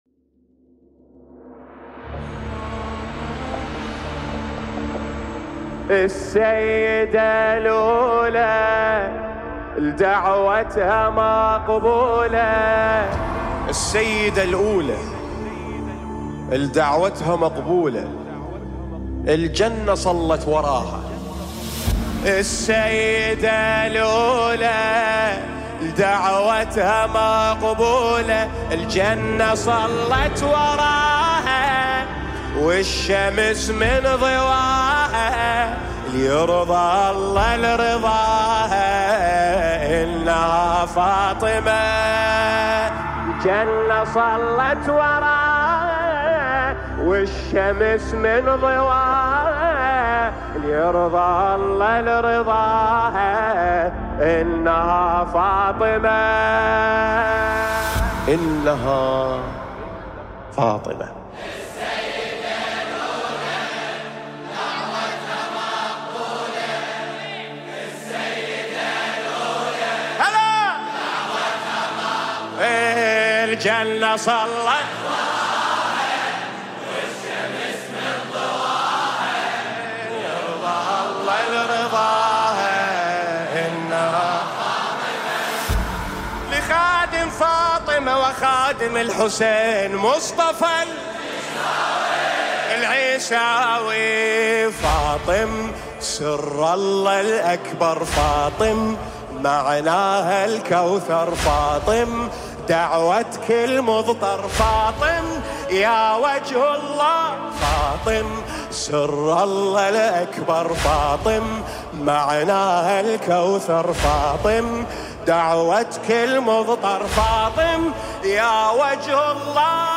مداحی عربی دلنشین